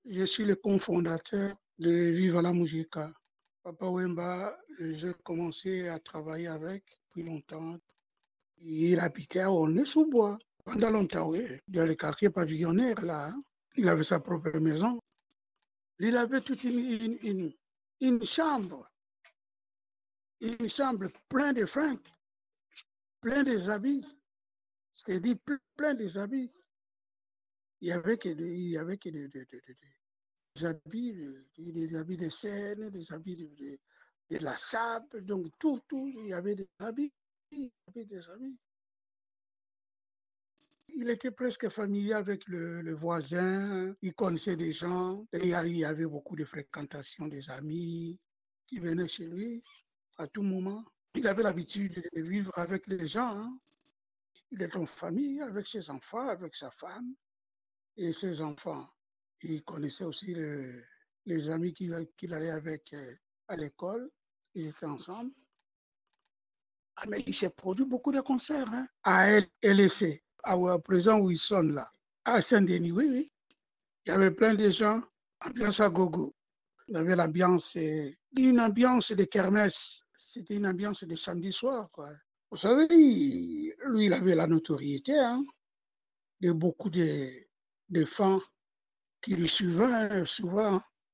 Interview à propos de Papa Wemba pour l’exposition Les musiciens africains racontent leur 93 Papa Wemba (1949-2016) RDC – Aulnay sous Bois -o- " "
PapaWemba-ITW.mp3